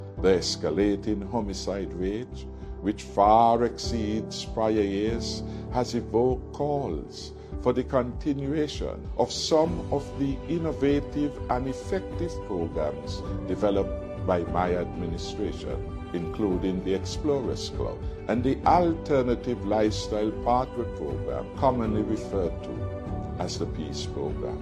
During an address to the nation on the occasion of the New Year, Former Prime Minister and Leader of the People’s Labour Party (PLP), Hon. Dr. Timothy Harris called on the current administration to tackle the issue of crime in St. Kitts and Nevis with “credible plans” during 2024.